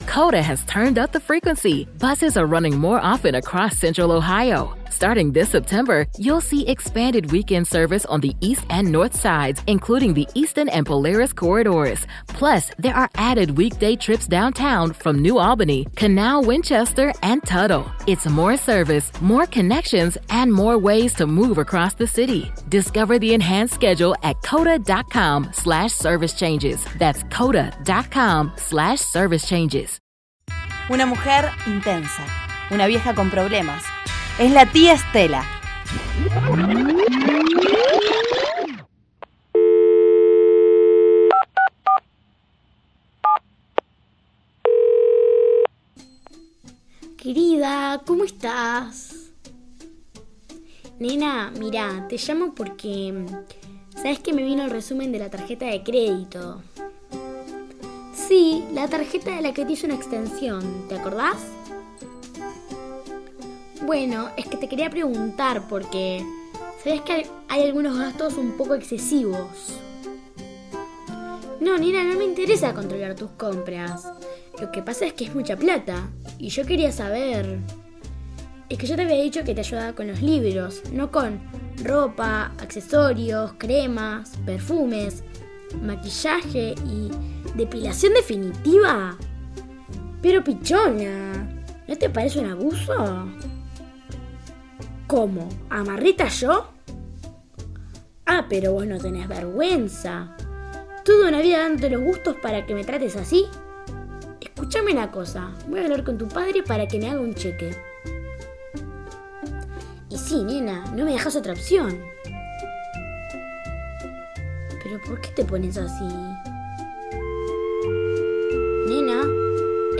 Se emite por Radio Sur FM 88.3